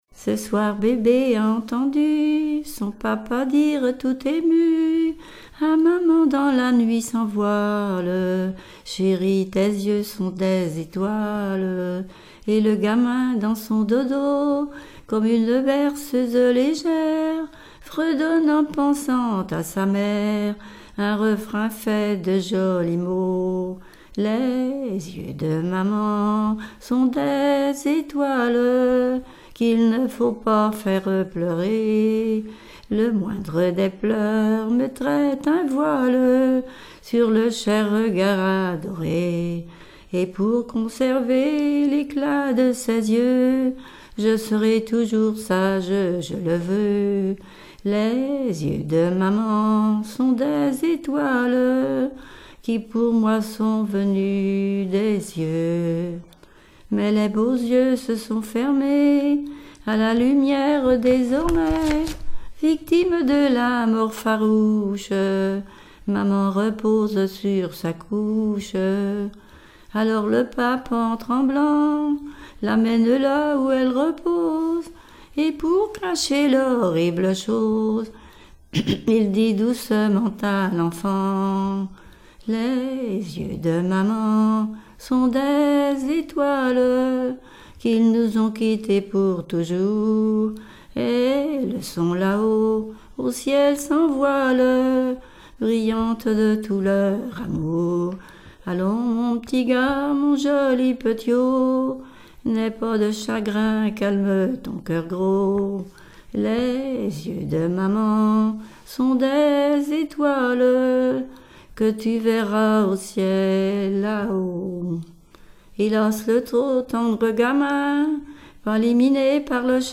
Genre strophique
Témoignages sur les conscrits et chansons
Pièce musicale inédite